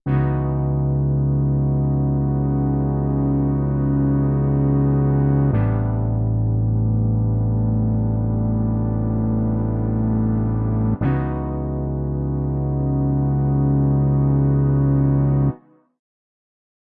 描述：简短的爵士乐序列。
标签： 介绍 爵士 结尾
声道立体声